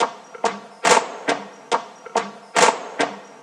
DDWV CLAP LOOP 2.wav